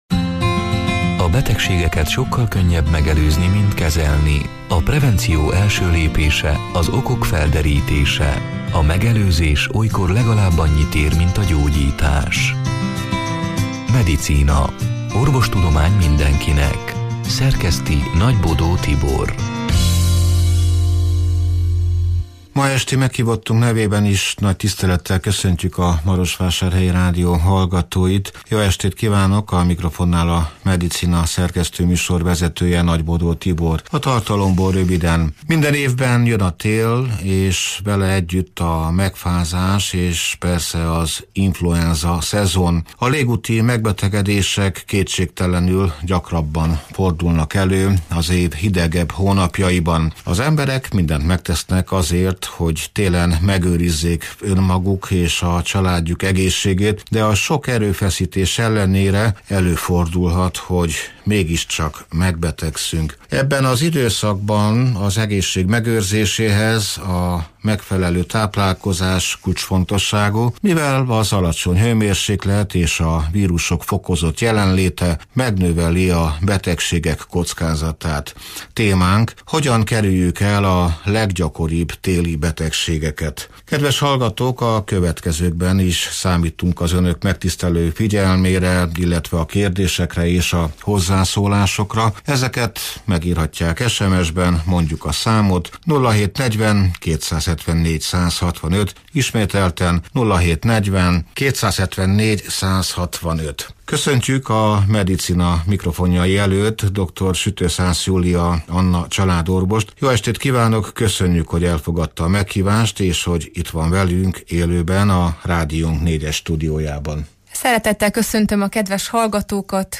(elhangzott: 2025. február 26-án, este nyolc órától élőben)